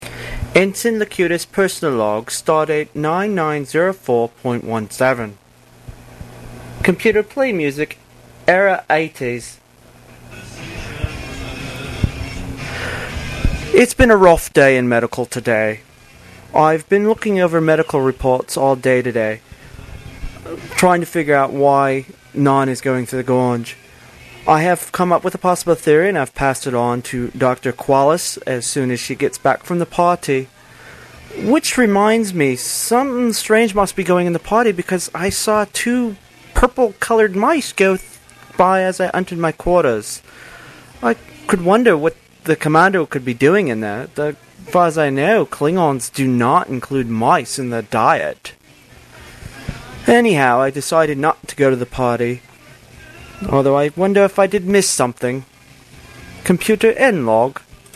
Here you will here some of the voice logs I did for the QOB.